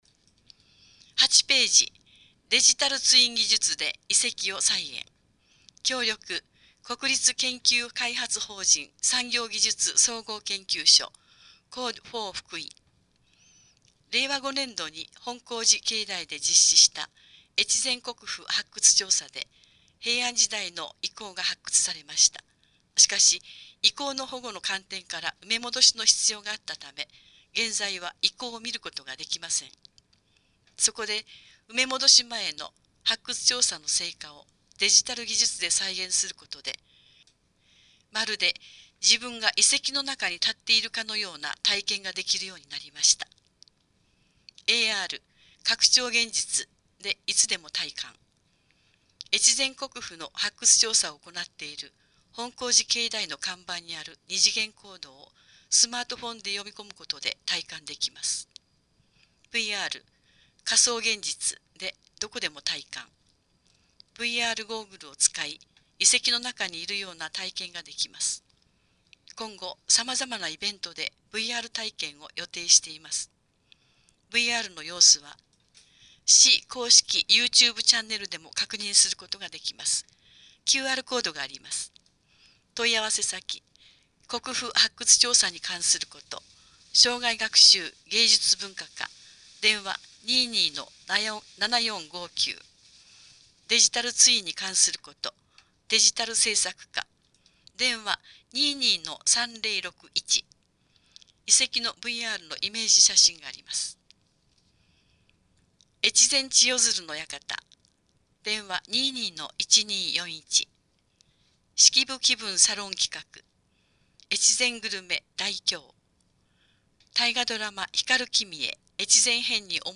越前市広報６月号（音訳）